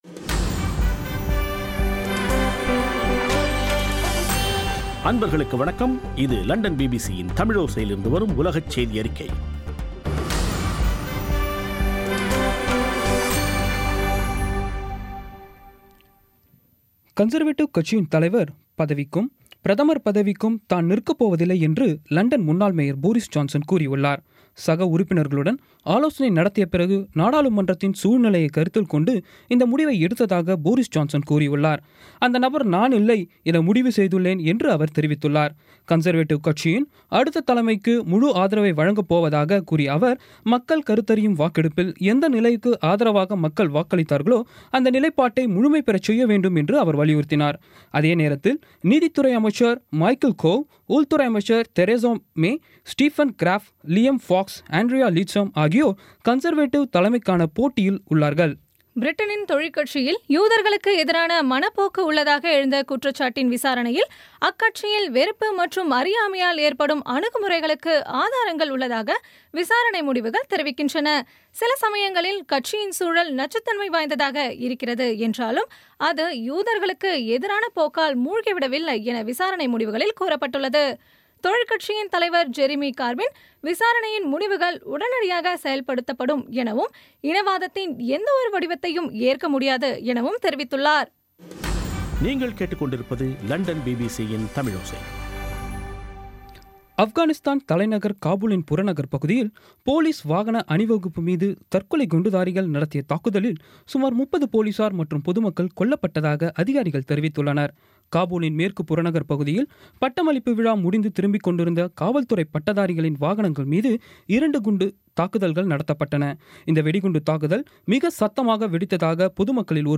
இன்றைய (ஜூன் 30ம் தேதி ) பிபிசி தமிழோசை செய்தியறிக்கை